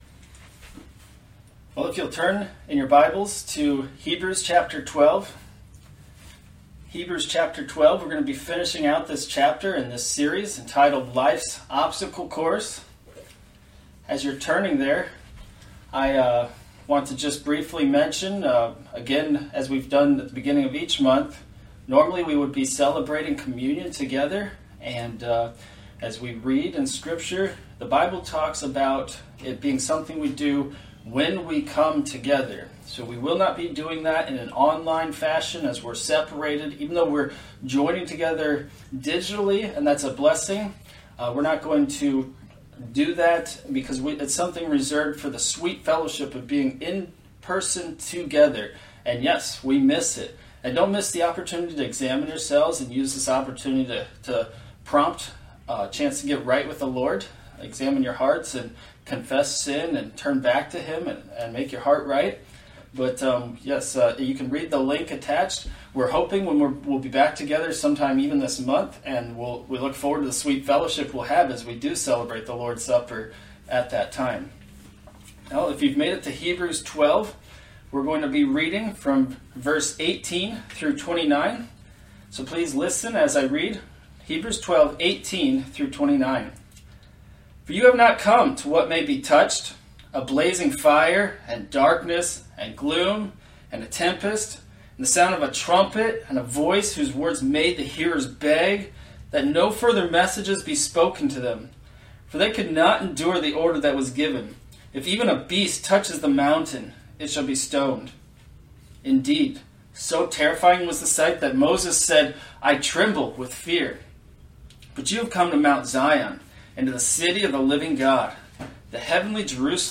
Online Sermons